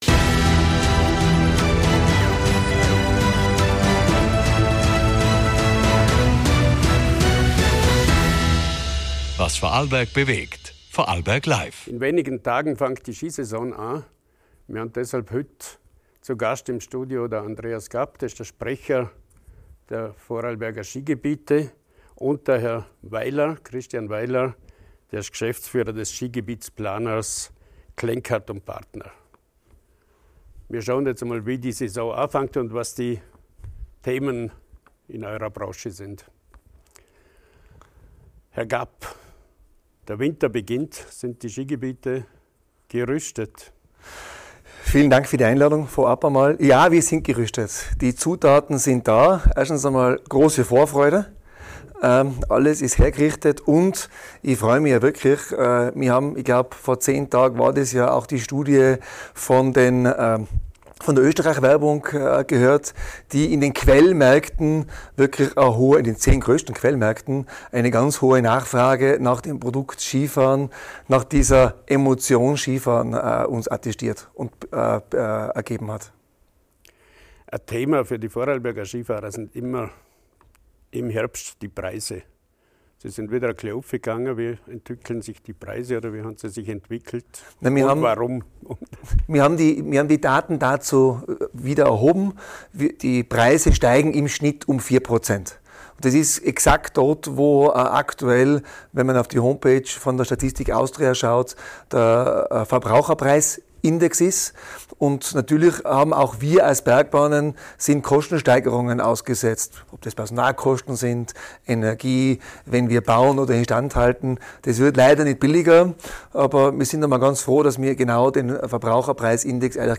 Das Gespräch beleuchtet: – die aktuellen Preistrends im Wintersport (u. a. +4 % im Durchschnitt) – wo rund 120 Mio. Euro investiert werden – allein in Vorarlberg – und warum Qualität vor Quantität steht – wie sich Skigebiete auf den Klimawandel und die Sommersaison einstellen Für alle, die verstehen wollen, wie Skibetrieb, Infrastruktur und Zukunftsfragen im Alpenraum ineinandergreifen.